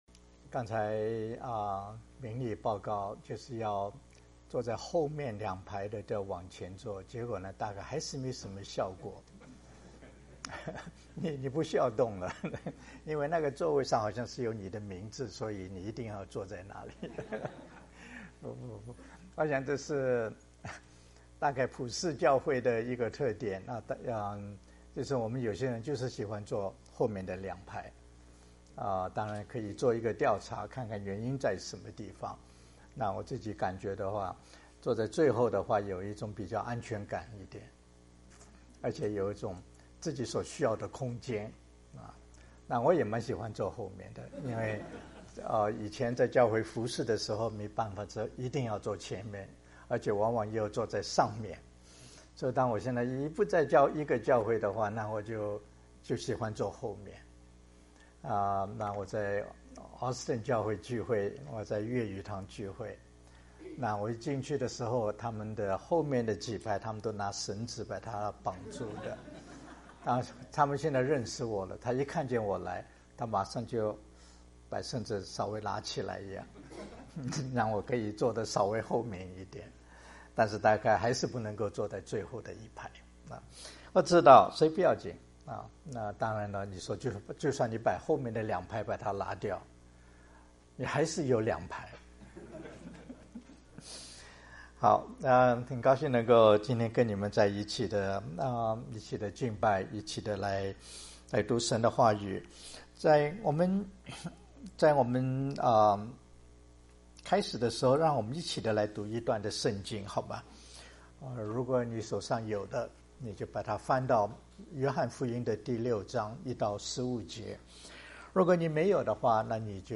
牧師 應用經文: 約翰福音6:1-15